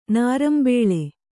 ♪ nārambēḷe